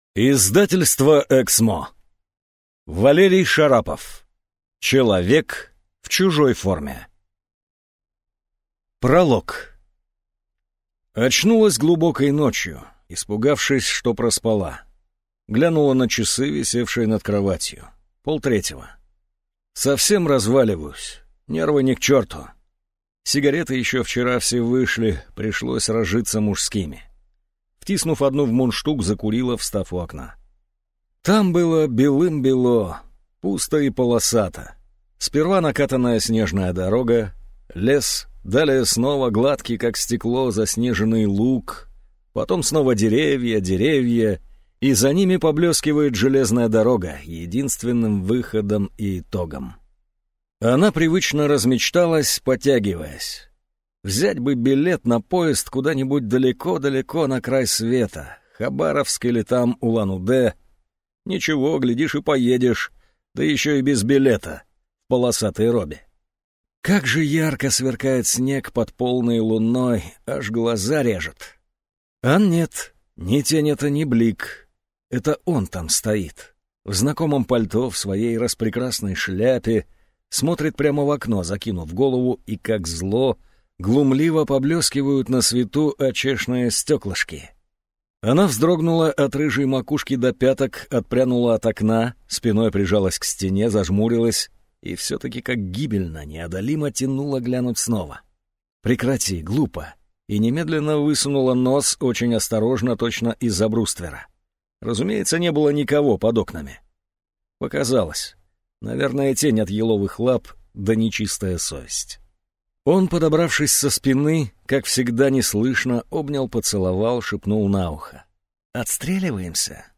Аудиокнига Человек в чужой форме | Библиотека аудиокниг